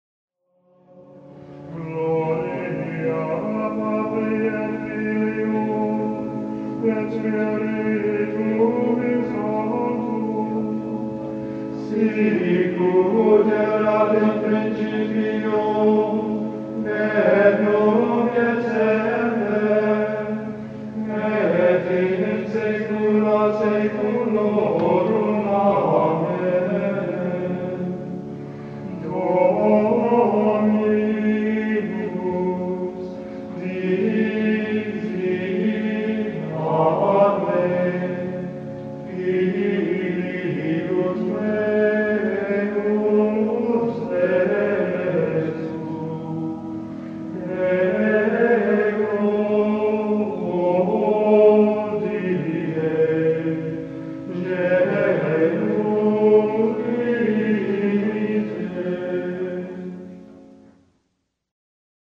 Gregorian Chant Exclusives